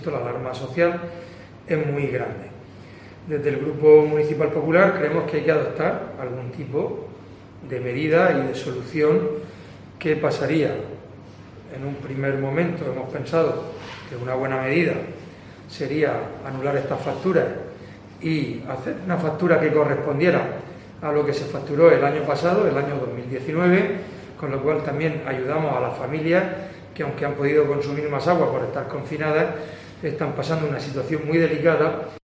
Fulgencio Gil, portavoz PP